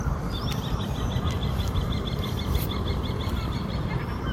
Birds -> Birds of prey ->
Common Kestrel, Falco tinnunculus
Administratīvā teritorijaRīga